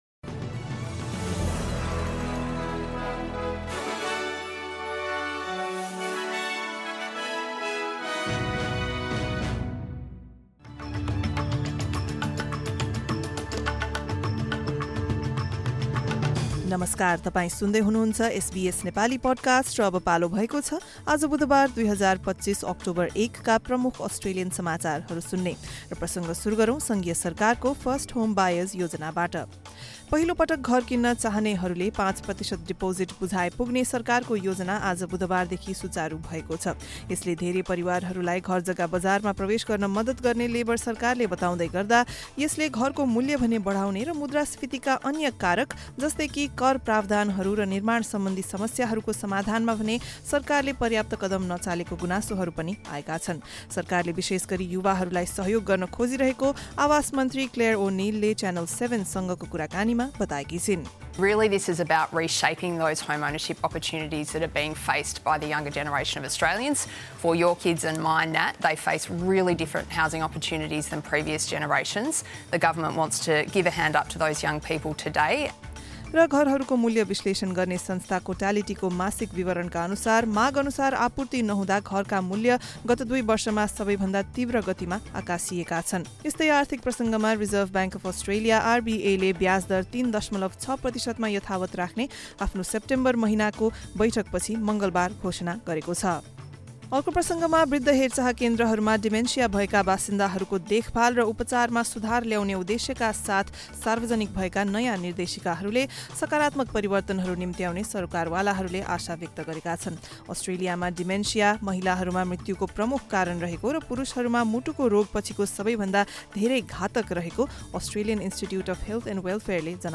एसबीएस नेपाली प्रमुख अस्ट्रेलियन समाचार: बुधवार, १ अक्टोबर २०२५